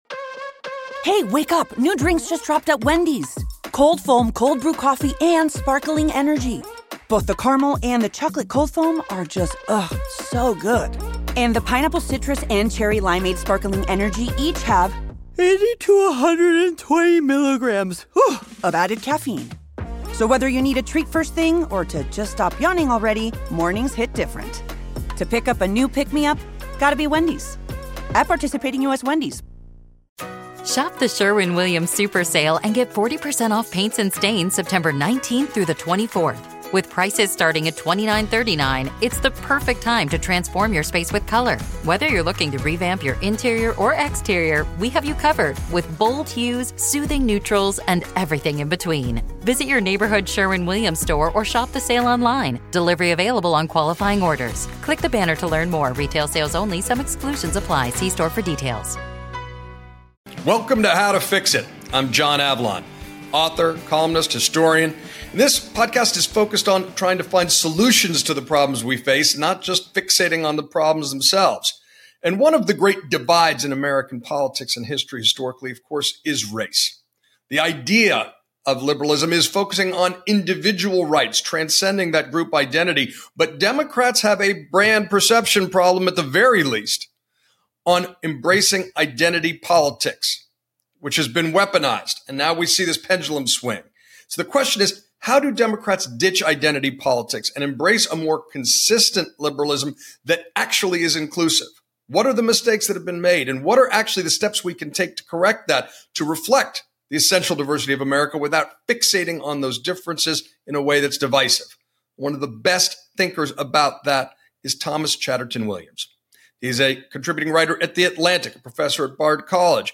John Avlon talks to Thomas Chatterton Williams on how to tackle one of the biggest challenges in American politics: Can Democrats move beyond identity politics and embrace a unifying vision of liberal democracy?